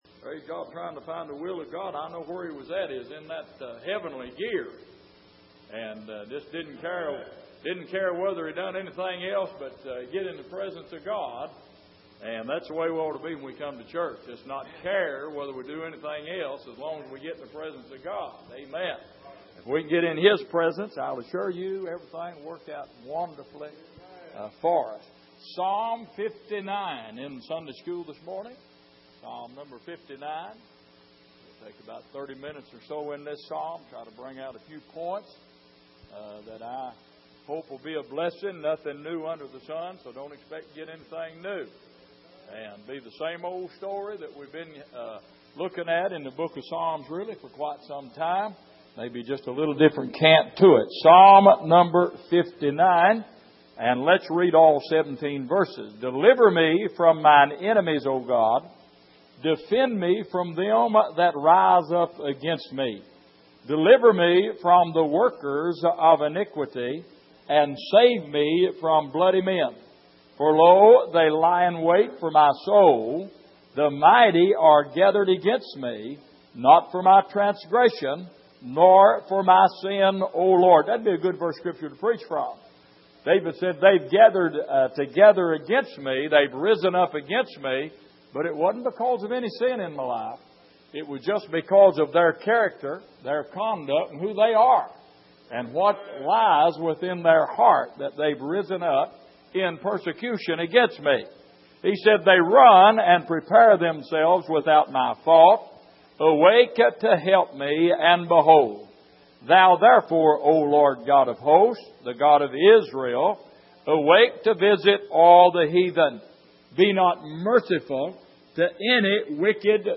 Passage: Psalm 59:1-17 Service: Sunday Morning